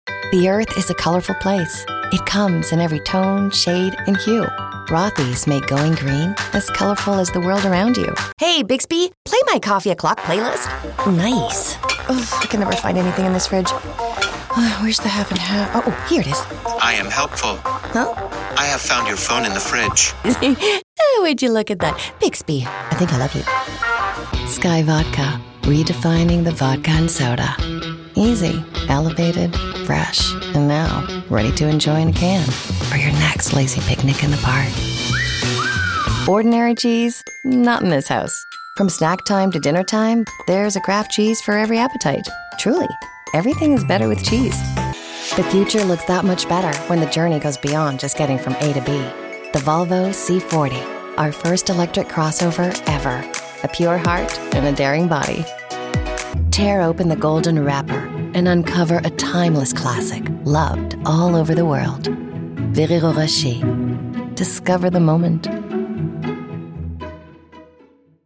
Demo
Adult, Young Adult
Has Own Studio
canadian | natural
standard us | natural